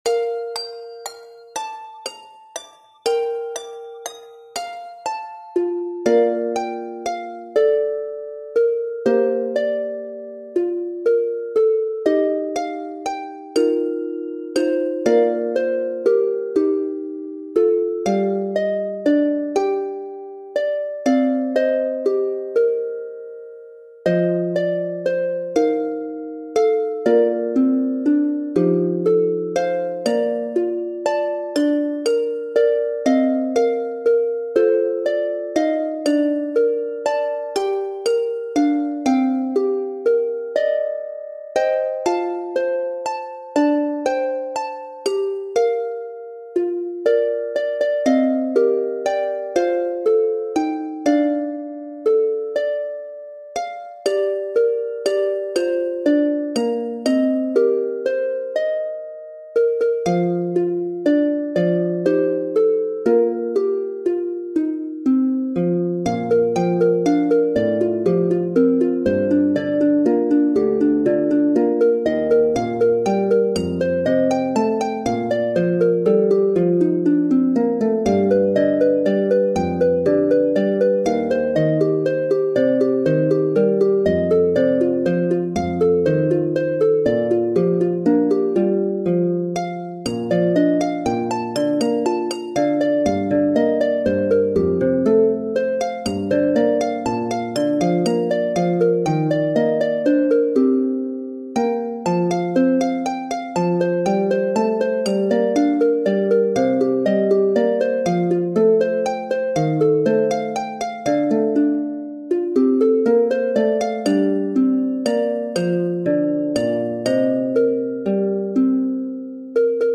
Harp Solo/Harp Optional Accompaniment/Harp Obbligato, Piano Solo
His Eye is on the Sparrow - arranged with the intent to introduce the new hymn to audiences. Originally arranged for lever harp tuned to Bb, but easily adjusted to play on pedal harp or piano.